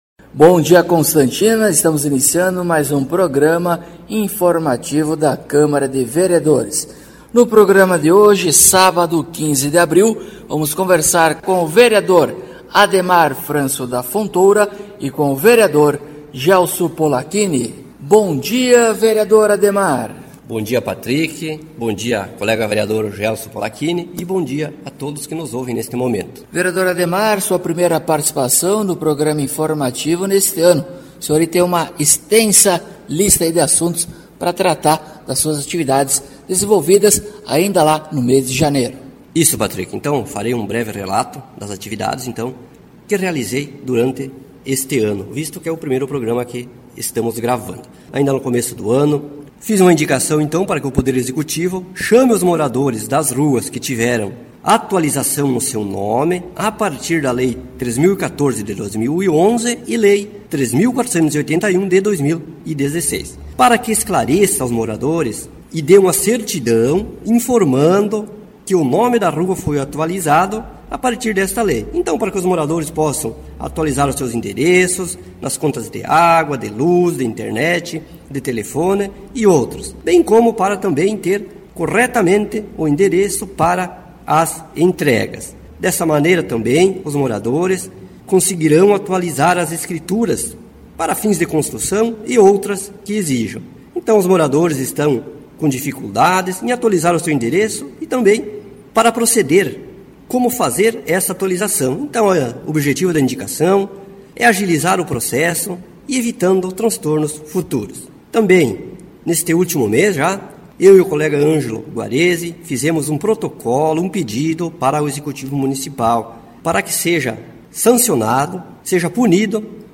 Acompanhe o programa informativo da câmara de vereadores de Constantina com o Vereador Ademar Francio da Fontoura e o Vereador Gelso Polaquini.